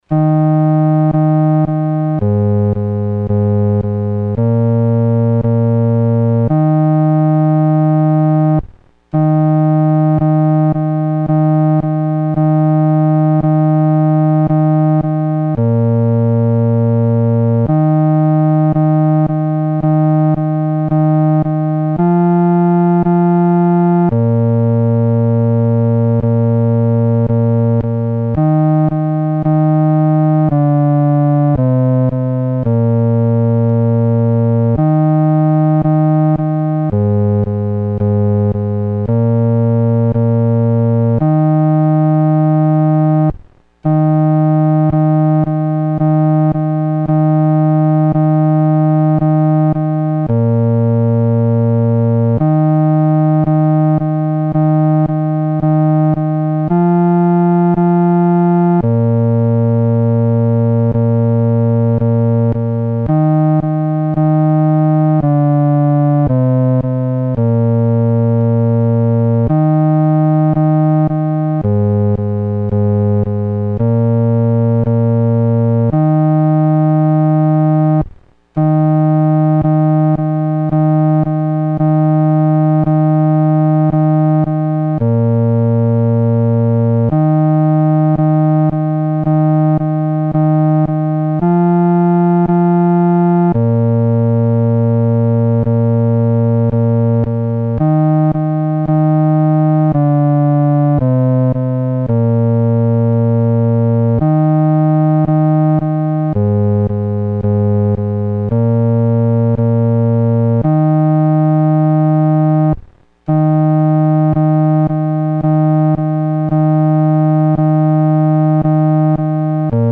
独奏（第四声）
求主掰开生命之饼-独奏（第四声）.mp3